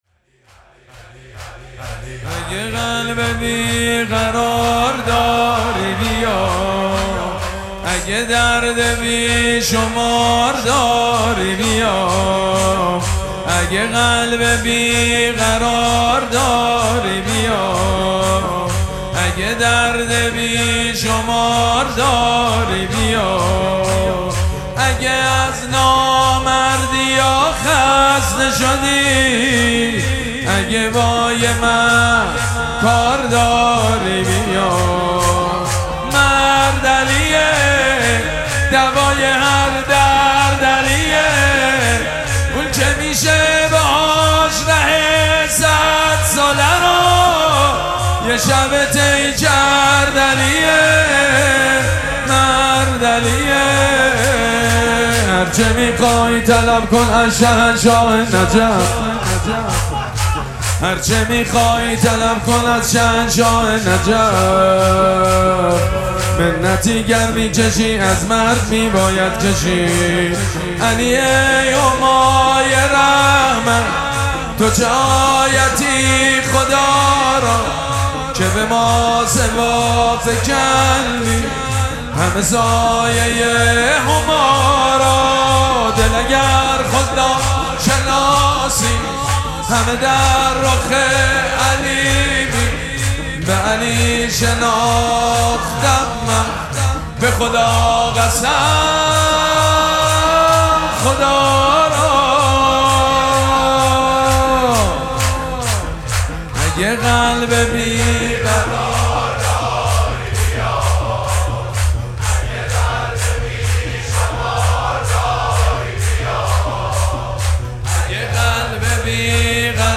مراسم مناجات شب نوزدهم ماه مبارک رمضان
شور
مداح
حاج سید مجید بنی فاطمه